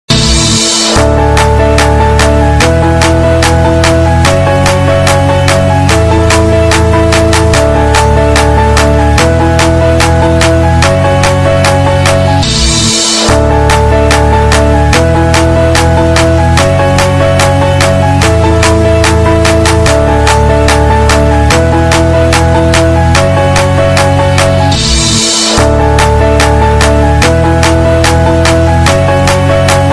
Жанр: Поп
# Mandopop